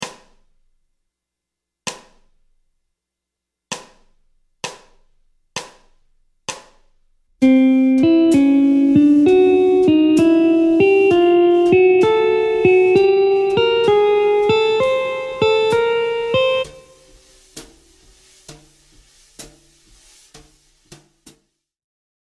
Mode Lydien b7 ( IV mineur mélodique)
{1 2 3 #4 5 6 b7}
Montée de gamme
Gamme-bop-asc-Pos-31-C-Lydien-b7.mp3